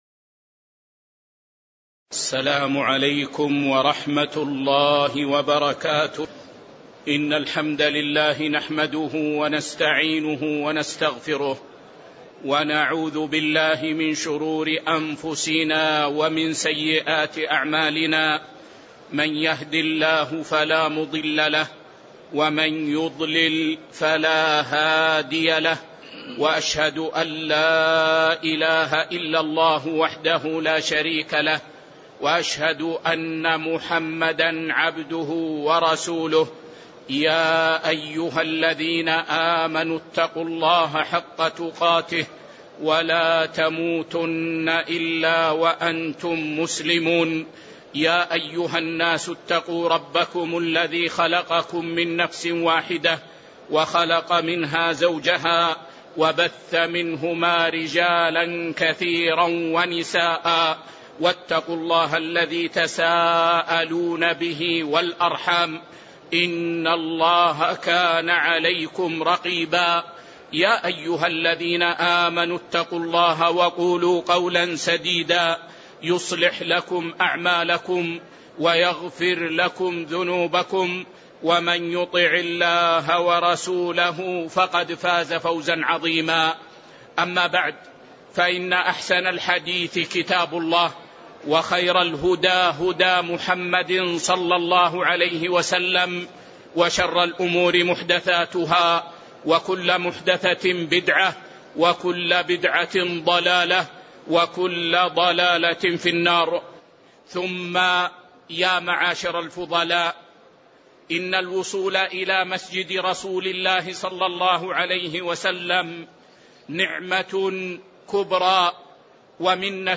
تاريخ النشر ٢٤ ذو الحجة ١٤٤٣ هـ المكان: المسجد النبوي الشيخ